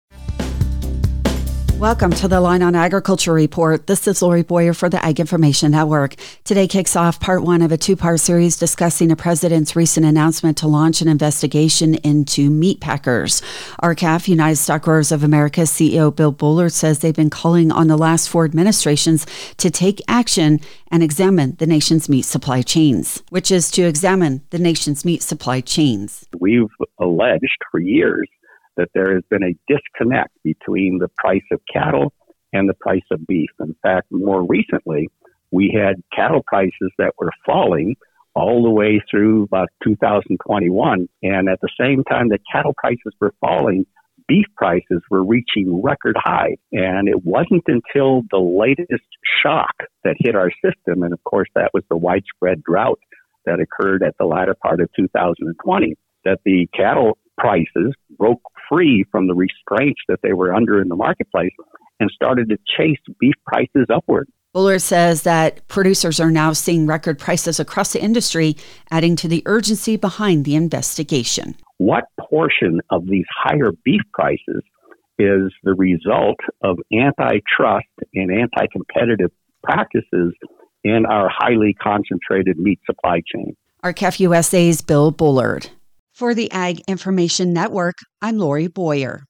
Reporter